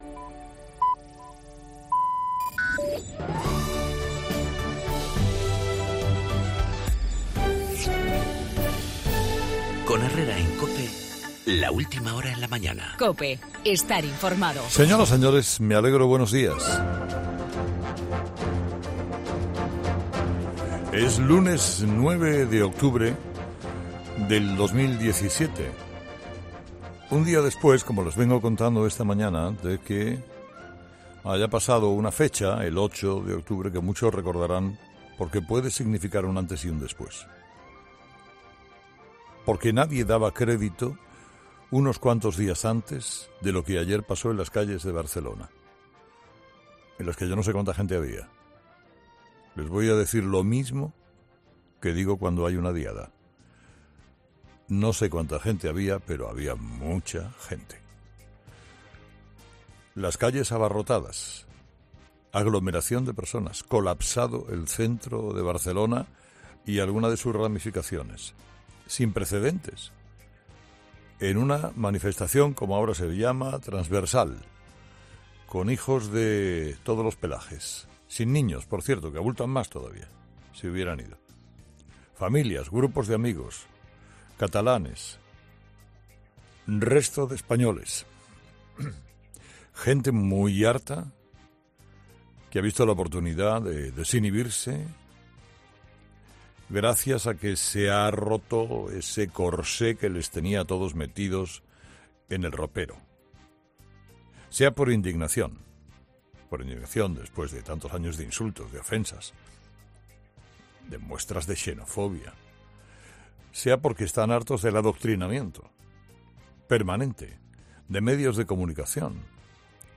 La masiva manifestación en la Ciudad Condal a favor de la unidad de España, en el editorial de Carlos Herrera